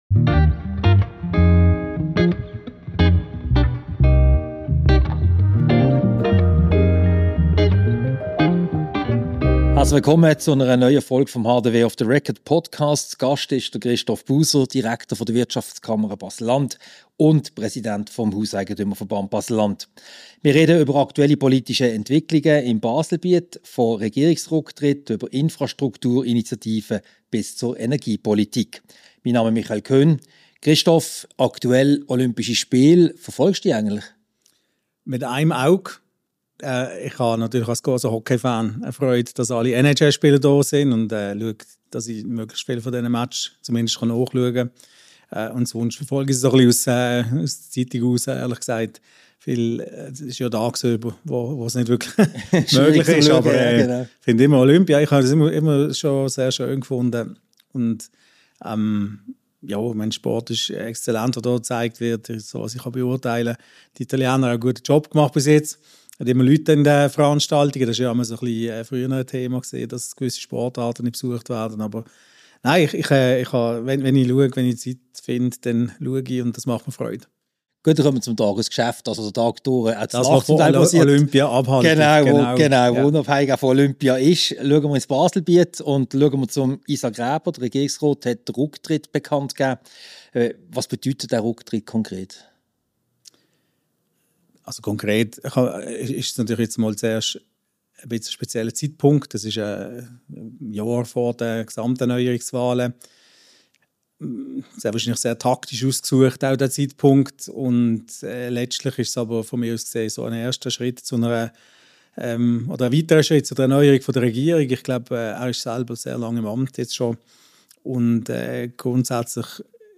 Ein Gespräch über die aktuellen politischen Entwicklungen im Baselbiet. Die Schwerpunkte sind der Rücktritt von Regierungsrat Isaac Reber, die Themen Infrastruktur, Energiepolitik und Verkehrspolitik sowie die Initiativen der Wirtschaftskammer und deren Rechtsgültigkeit.